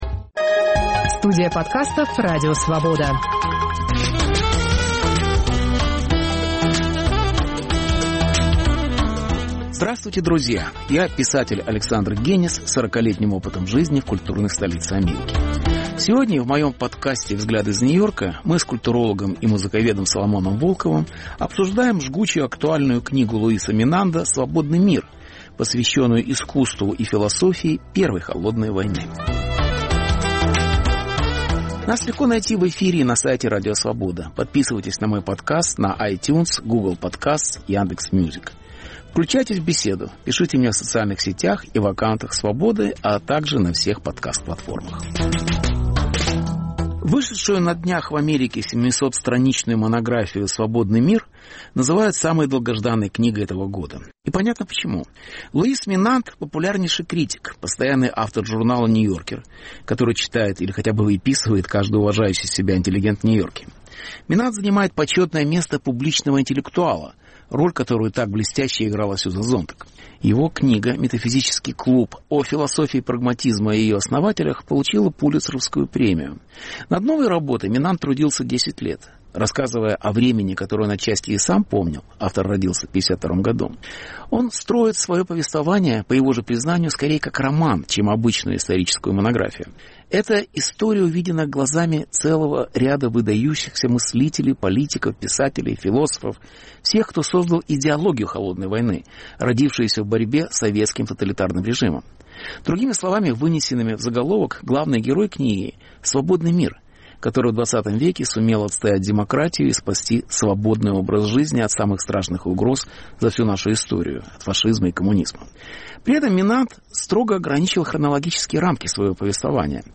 Беседа с Соломоном Волковым о борьбе идей и книге Луиса Менанда "Свободный мир".